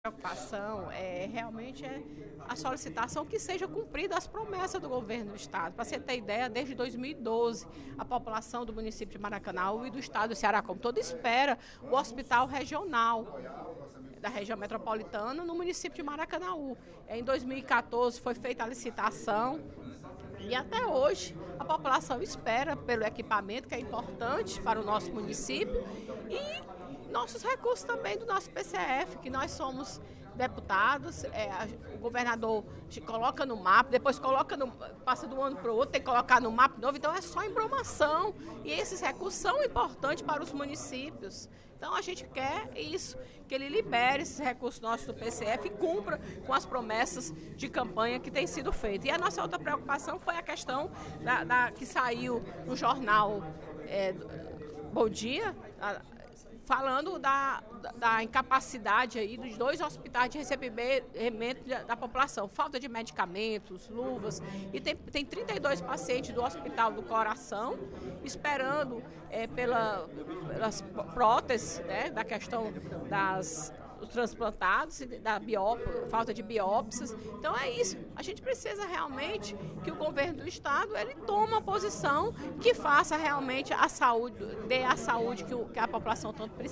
A deputada Fernanda Pessoa (PR) destacou, durante o primeiro expediente da sessão plenária desta terça-feira (09/05), matéria veiculada no Bom Dia Brasil, da Rede Globo, que denunciou a falta de materiais básicos em dois grandes hospitais da capital cearense.